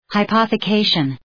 Προφορά
{haı,pɒɵə’keıʃən} (Ουσιαστικό) ● υποθήκευση